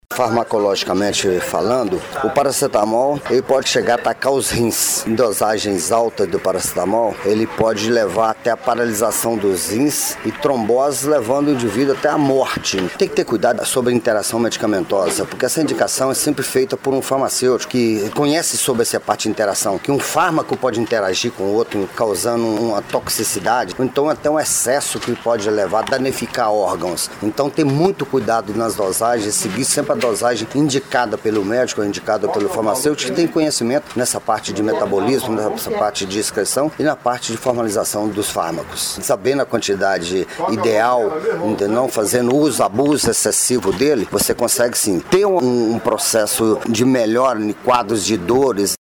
O Jornal da Manhã conversou com o farmacêutico